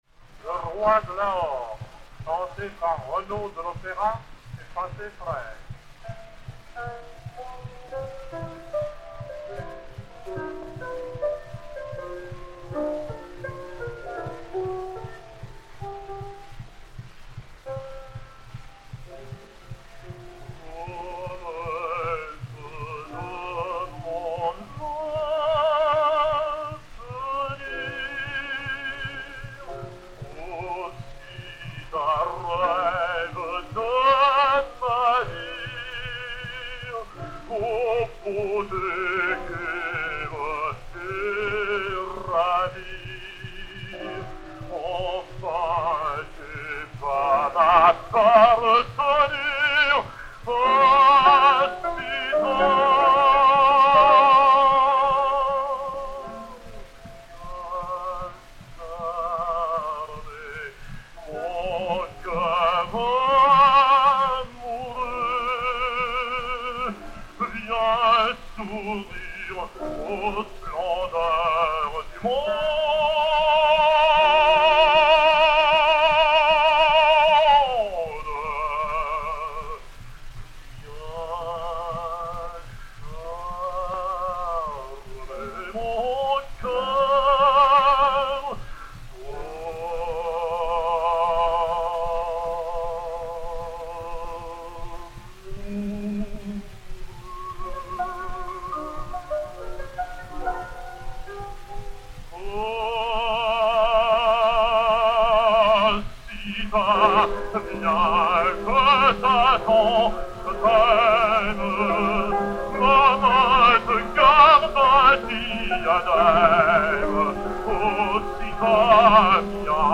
Arioso
Maurice Renaud (Scindia) et Piano